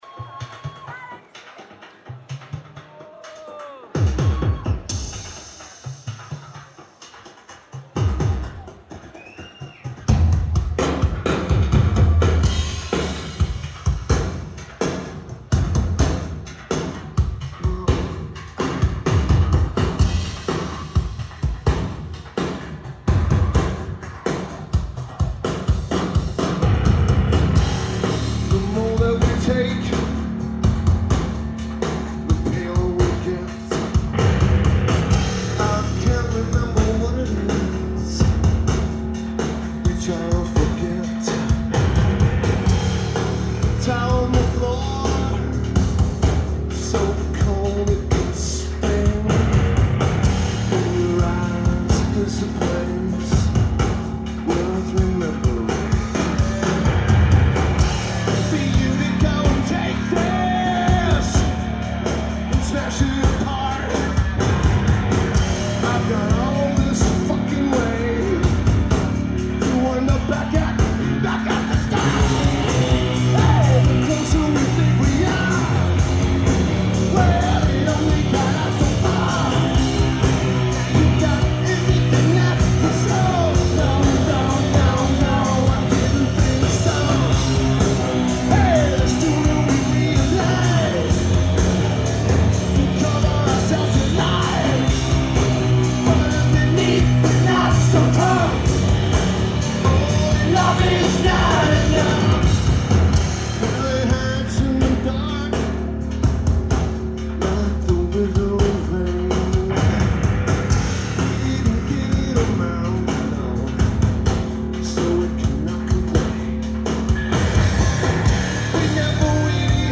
Birmingham, England United Kingdom
Lineage: Audio - AUD (Sony ECM-717 + Sony MZ-N710 [LP2])
It's an absolutely fantastic tape.